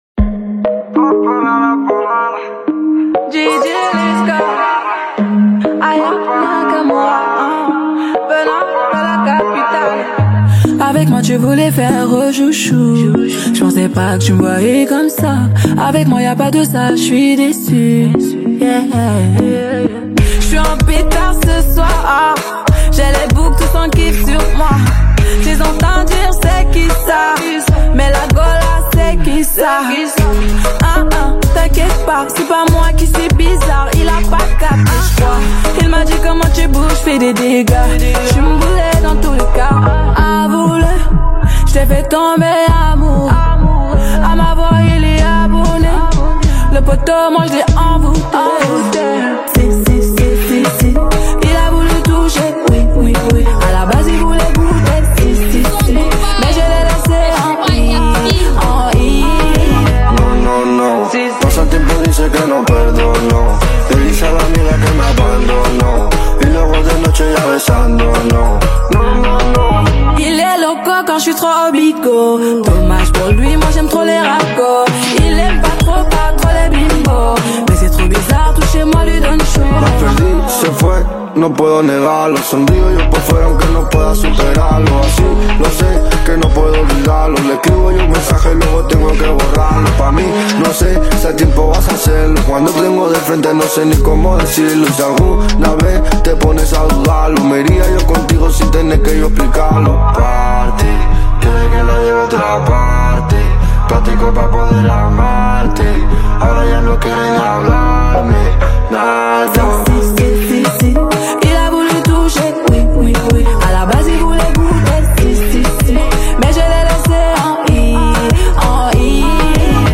Afro Beat